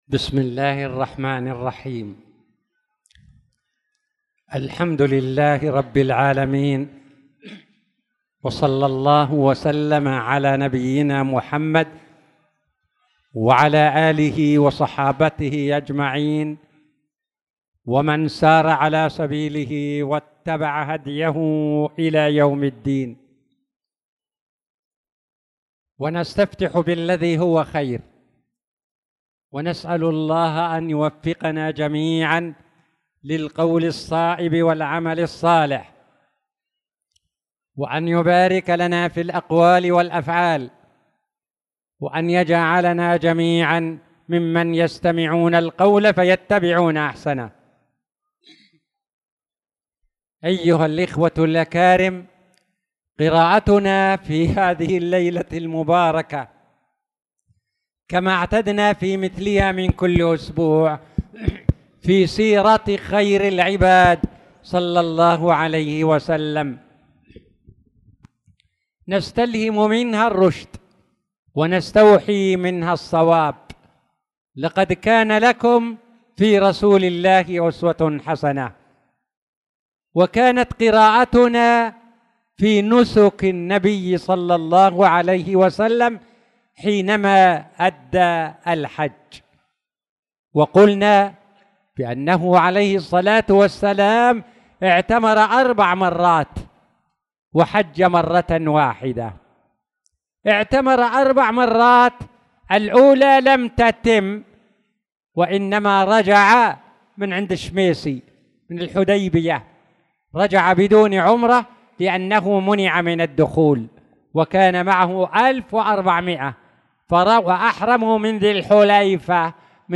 تاريخ النشر ٩ شوال ١٤٣٧ هـ المكان: المسجد الحرام الشيخ